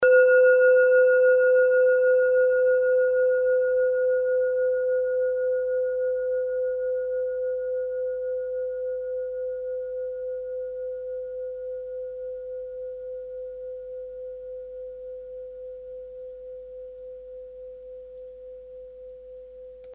Kleine Klangschale Nr.2 Bengalen Planetentonschale: Meisterton
Kleine Klangschale Nr.2
Klangschale-Durchmesser: 11,5cm
Sie ist neu und ist gezielt nach altem 7-Metalle-Rezept in Handarbeit gezogen und gehämmert worden.
(Ermittelt mit dem Minifilzklöppel)
kleine-klangschale-2.mp3